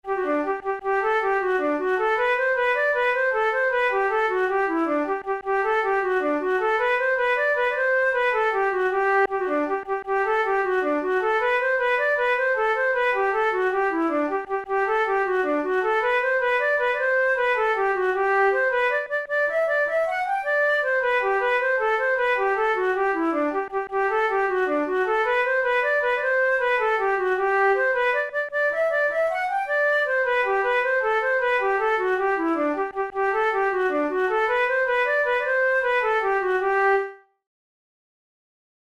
InstrumentationFlute solo
KeyG major
RangeD4–G5
Time signature6/8
Tempo104 BPM
Jigs, Traditional/Folk
Traditional Irish jig